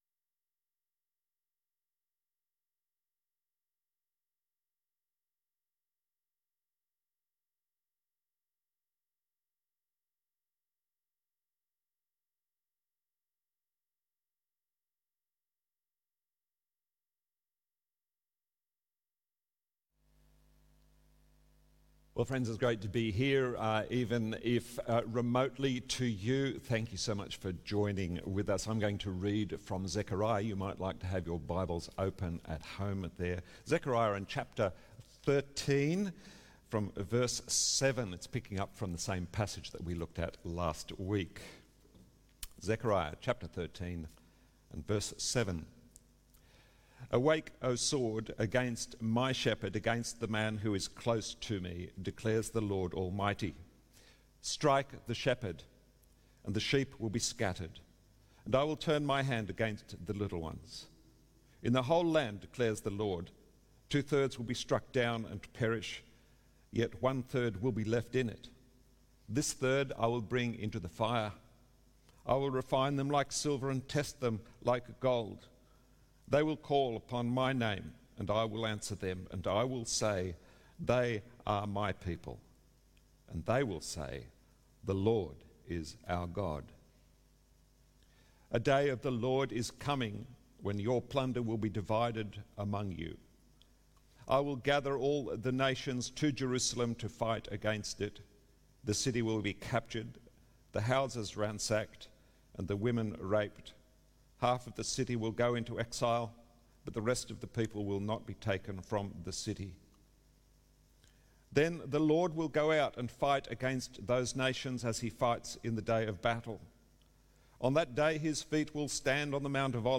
Zechariah 13:7-14:5 Sunday sermon